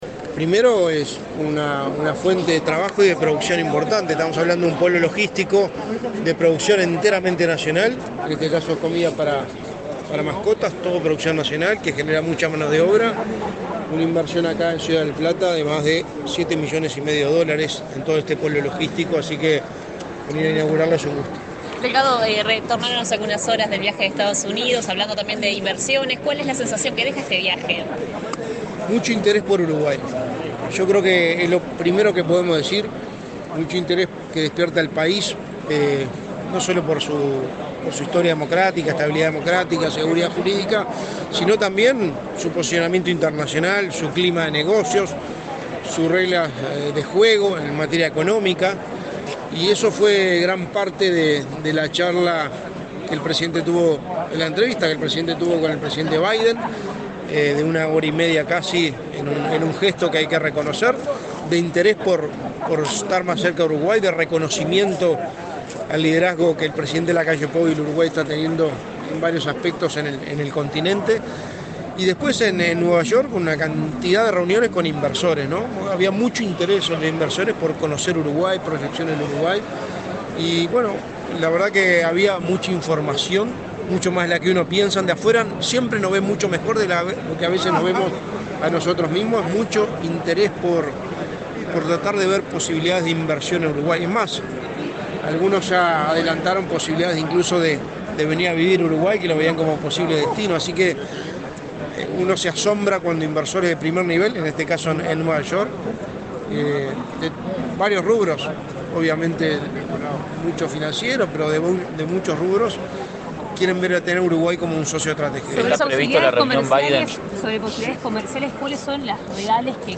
Declaraciones del secretario de Presidencia, Álvaro Delgado
Declaraciones del secretario de Presidencia, Álvaro Delgado 15/06/2023 Compartir Facebook X Copiar enlace WhatsApp LinkedIn El secretario de la Presidencia, Álvaro Delgado, dialogó con la prensa luego de participar en la inauguración del Polo Logístico Industrial Ciudad del Plata, en el departamento de San José.